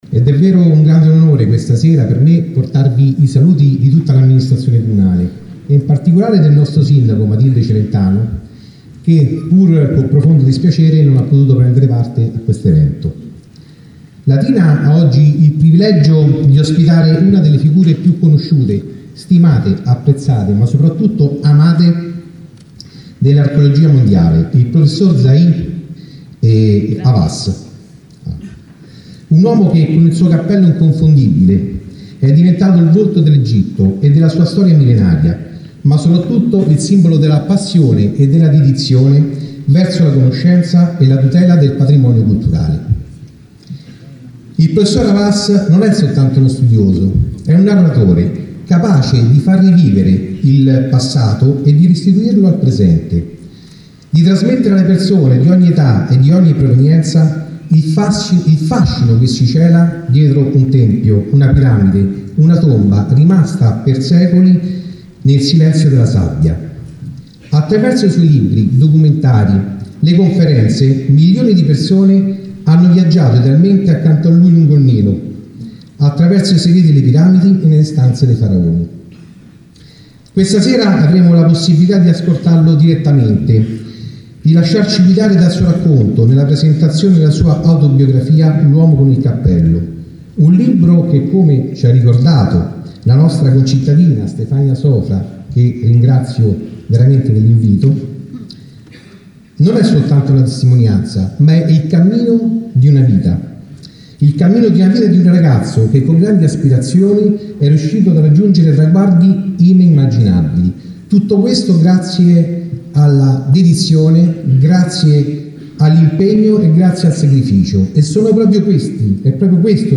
Il saluto del vince sindaco di Latina Massimiliano Carnevale
carnevale-discorso.mp3